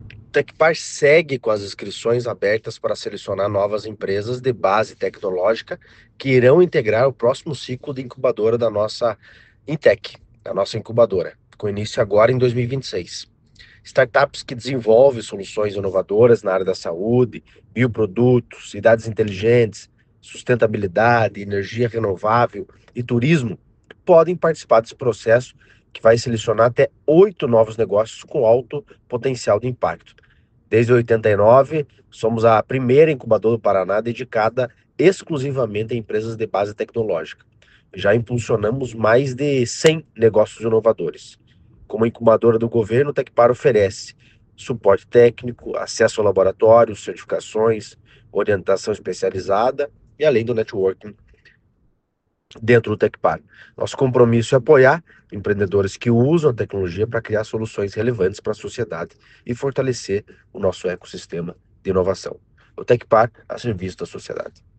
Sonora do diretor-presidente do Tecpar, Eduardo Marafon, sobre o novo ciclo de incubação do Instituto